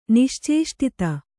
♪ niścēṣṭita